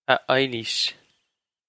Ah Eye-leesh
This is an approximate phonetic pronunciation of the phrase.